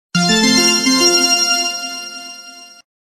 Victory sound effects (no copyright).mp3